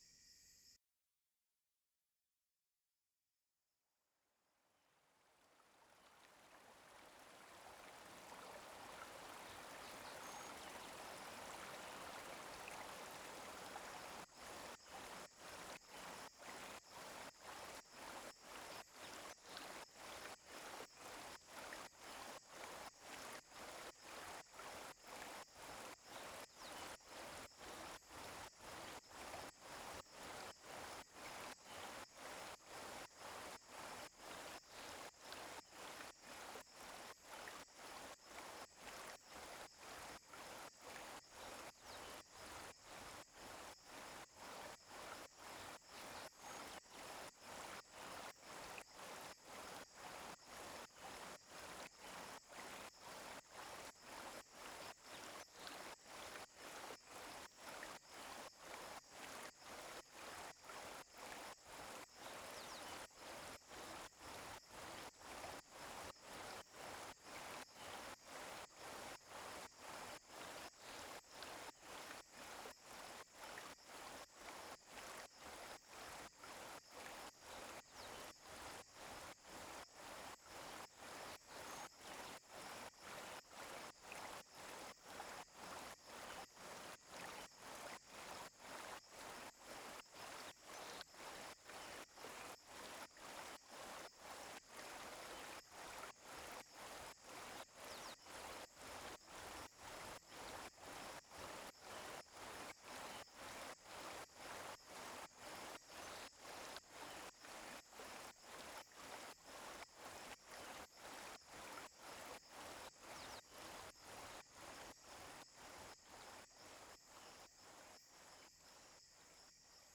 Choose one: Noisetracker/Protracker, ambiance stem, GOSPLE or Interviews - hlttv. ambiance stem